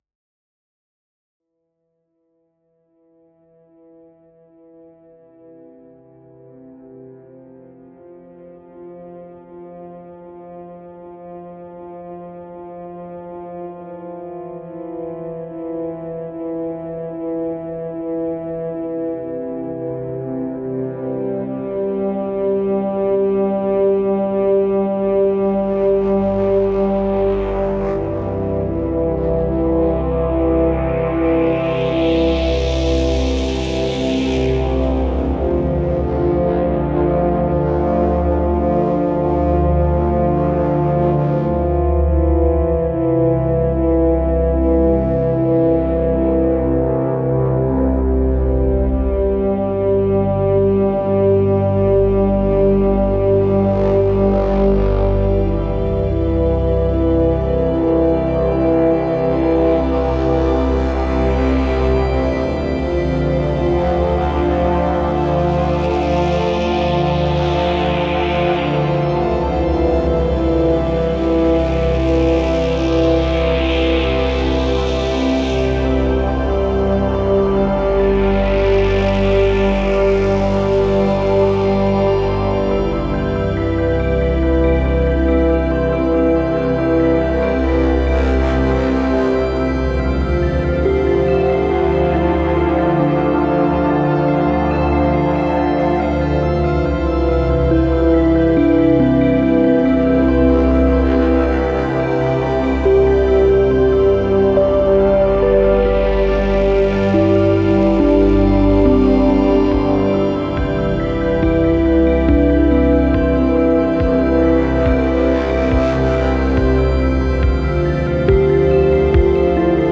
chill / downtempo / ambient